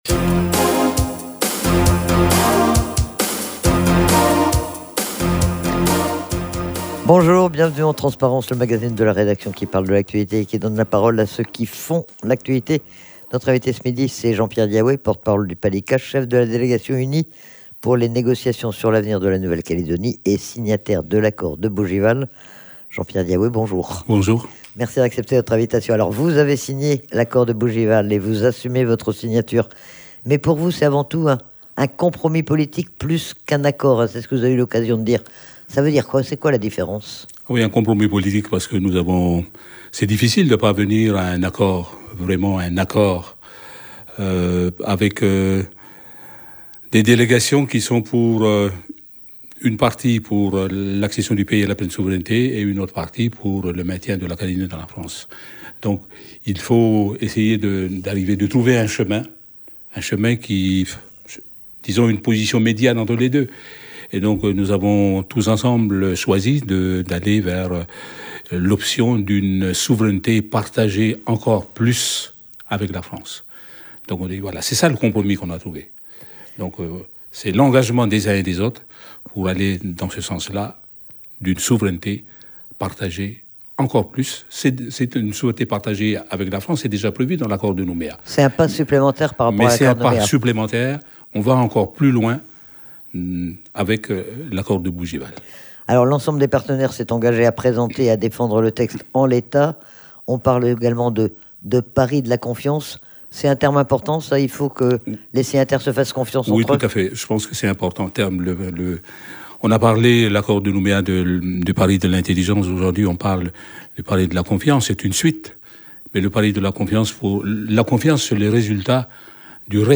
Jean-Pierre Djaiwé, l'un des porte-paroles du PALIKA était l'invité du magazine Transparence.
Il a été interrogé sur le contenu de cet accord, sur les raisons qui l'ont amené à le signer et sur l'interprétation qu'il en fait. Il a également réagi au rejet de cet accord par l'Union calédonienne.